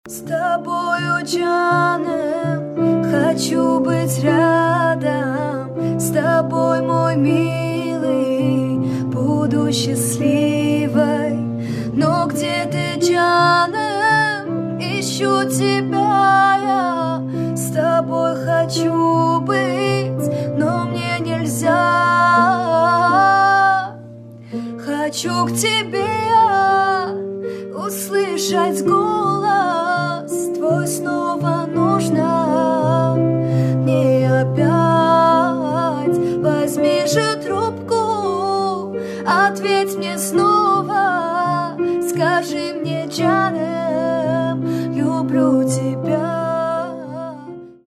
• Качество: 320, Stereo
грустные
Cover
восточные